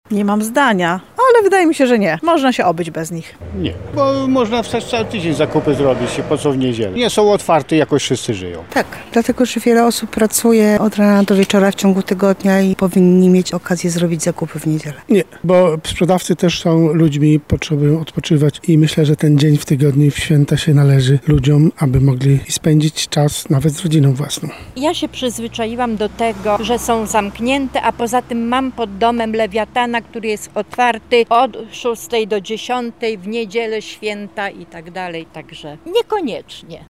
[SONDA:] Czy sklepy powinny być otwarte w niedzielę?
Zapytaliśmy mieszkańców Lublina, czy ich zdaniem sklepy powinny być otwarte w niedzielę: